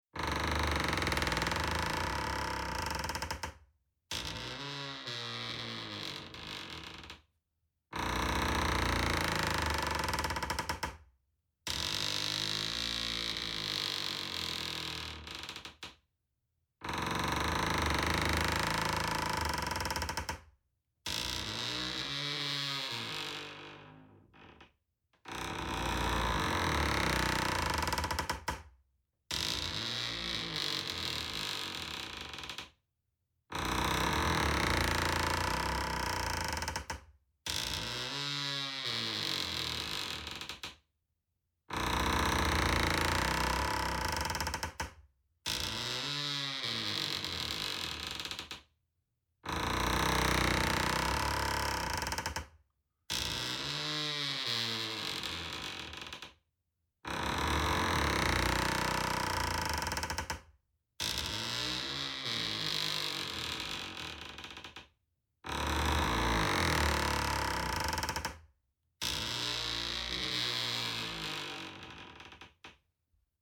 Деревянный скрип палубы старого корабля звук